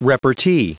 Prononciation du mot repartee en anglais (fichier audio)
Prononciation du mot : repartee